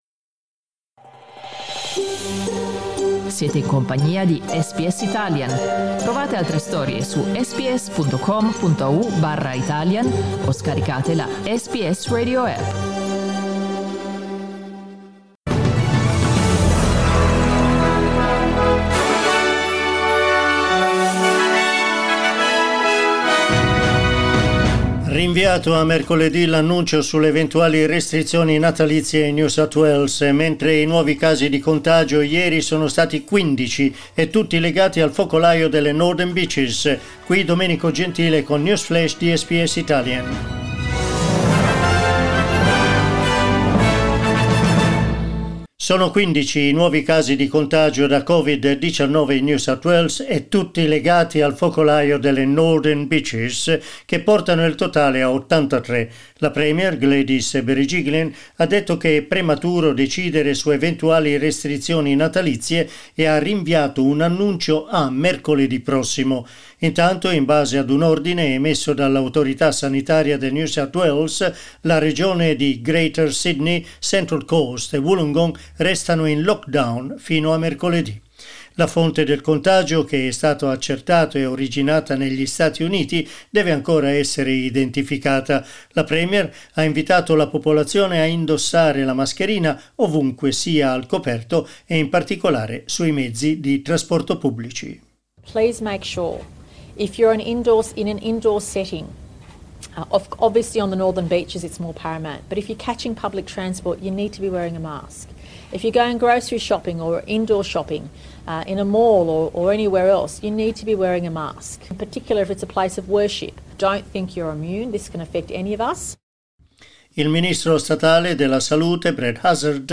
Our news update in Italian.